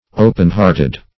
Open-hearted \O"pen-heart`ed\, a.